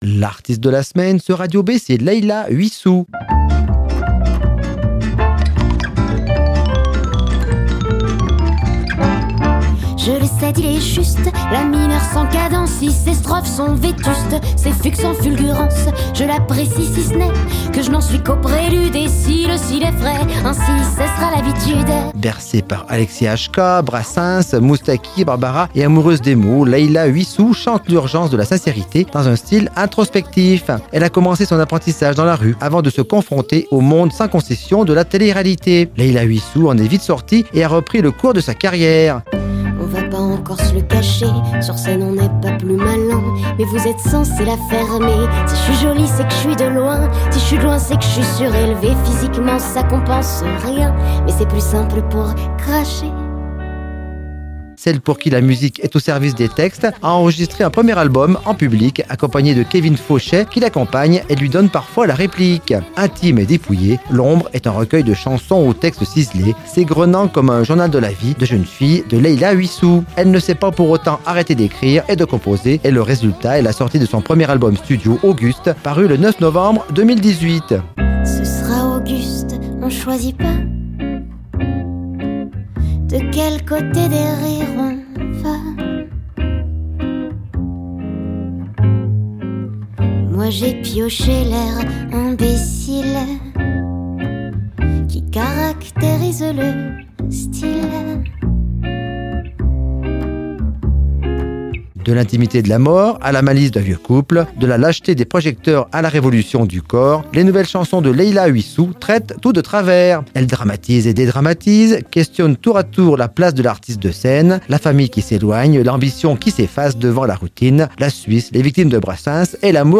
dans un style introspectif